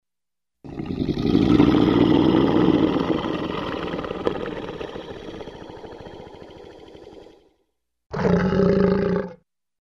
Звуки рычания льва
Спокойное рычание царя зверей